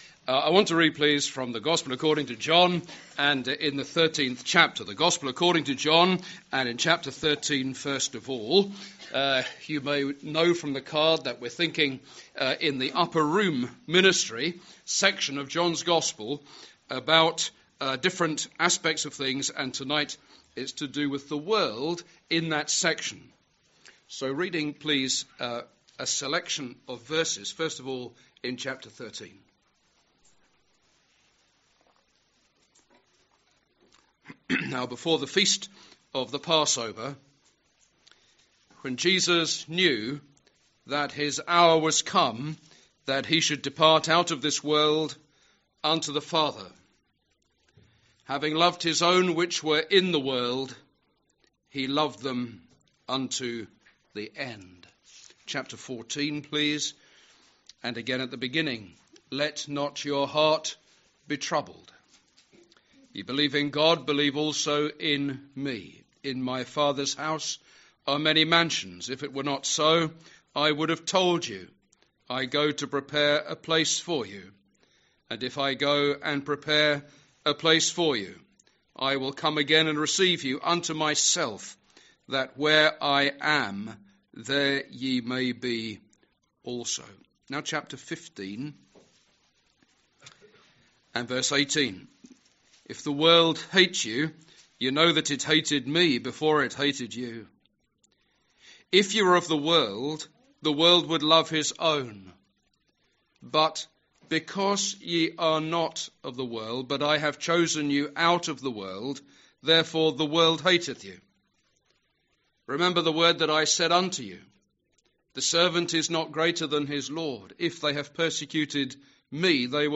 Passage: John 13:1, 14:1-3, 15:18-21, 16:7-11, 17:6, 11-19 Service Type: Ministry